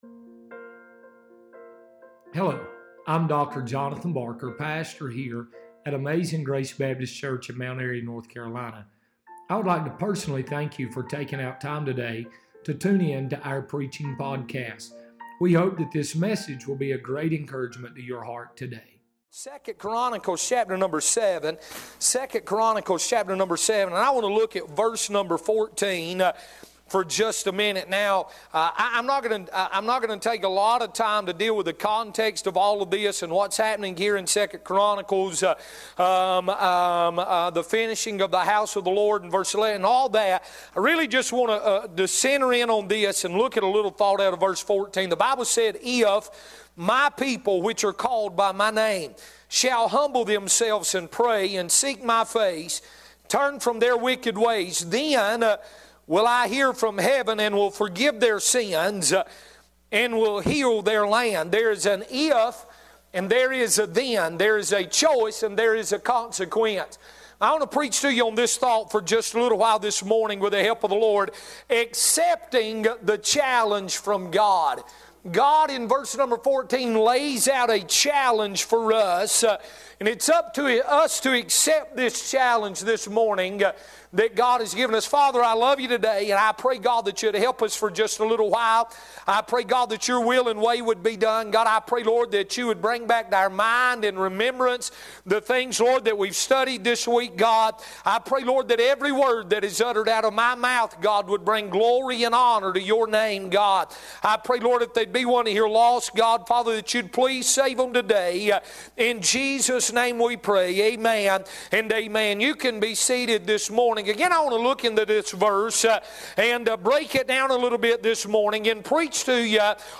Sermons | Amazing Grace Baptist Church